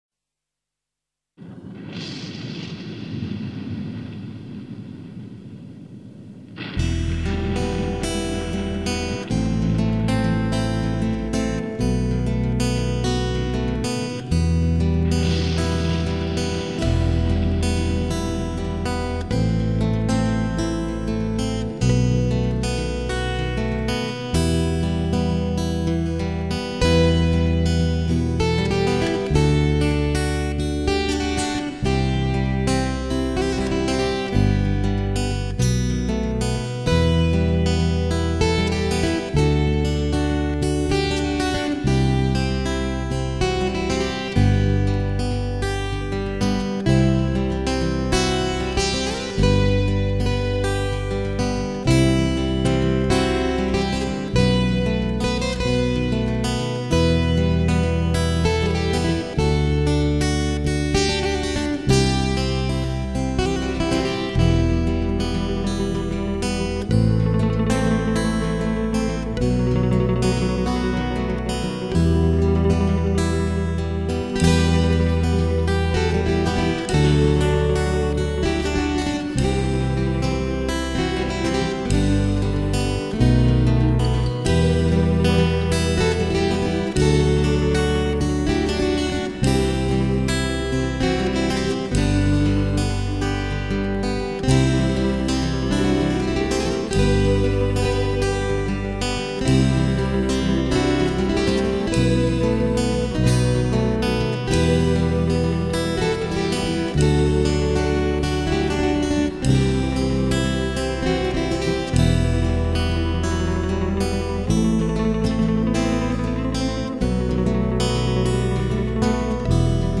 guitare et mandoline corses
Guitare et mandoline